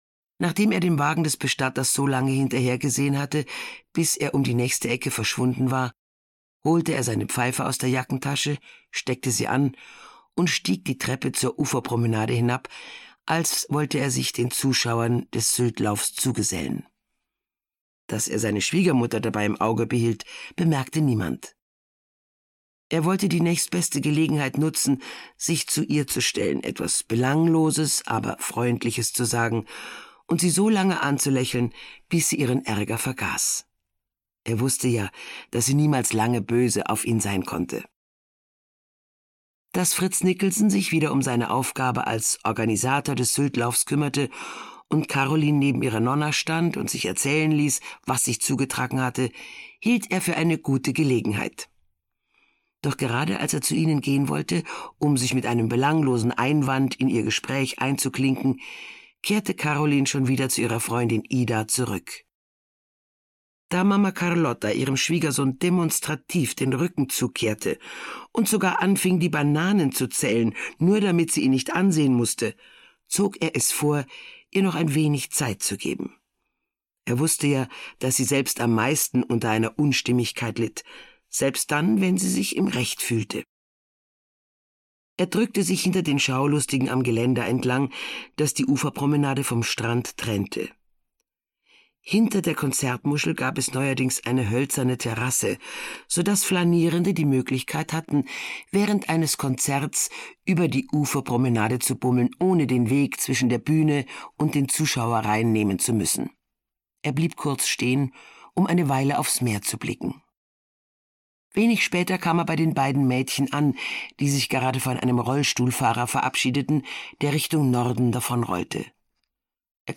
Gegenwind (Mamma Carlotta 10) - Gisa Pauly - Hörbuch